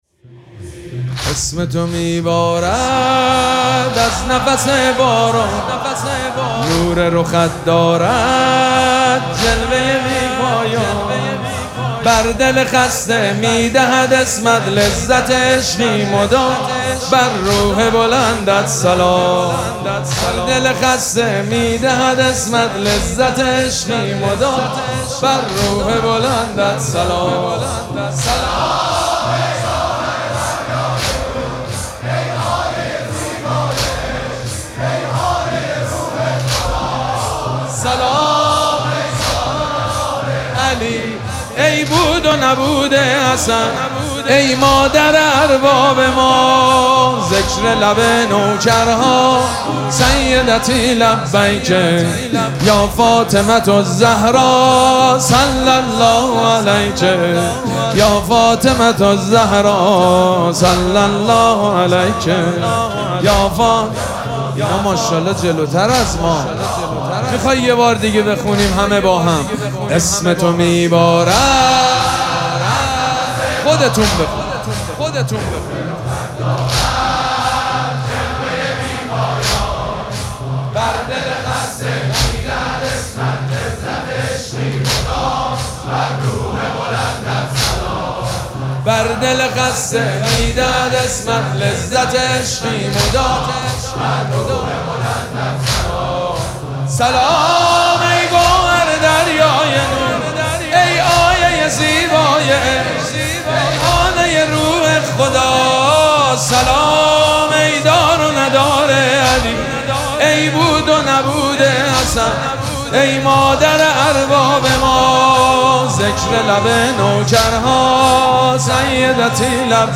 شب پنجم مراسم عزاداری دهه دوم فاطمیه ۱۴۴۶
حسینیه ریحانه الحسین سلام الله علیها
مداح
حاج سید مجید بنی فاطمه